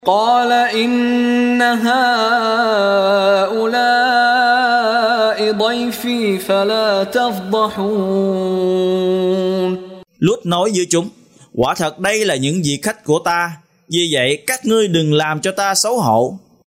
Đọc ý nghĩa nội dung chương Al-Hijr bằng tiếng Việt có đính kèm giọng xướng đọc Qur’an